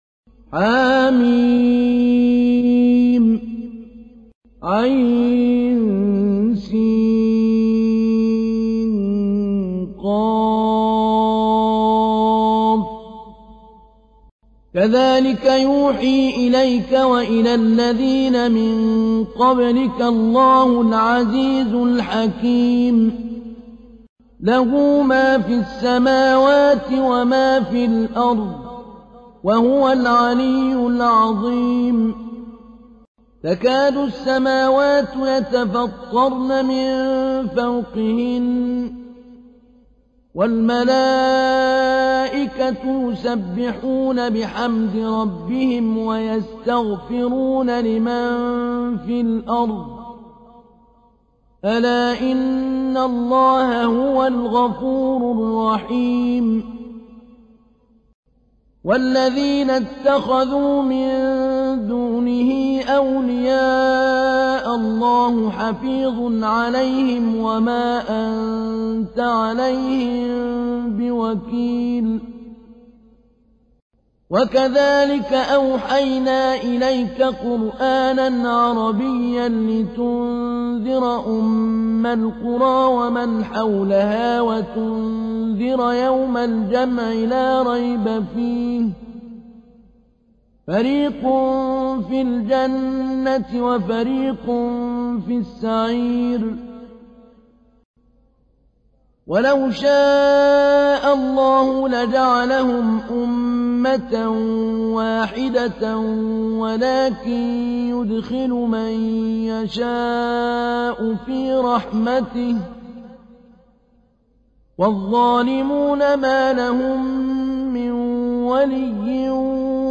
تحميل : 42. سورة الشورى / القارئ محمود علي البنا / القرآن الكريم / موقع يا حسين